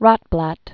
(rŏtblăt), Joseph 1908-2005.